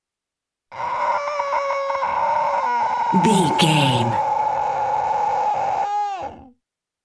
Monster pain growl little creature
Sound Effects
scary
ominous
eerie